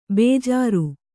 ♪ bējāru